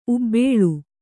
♪ ubbēḷu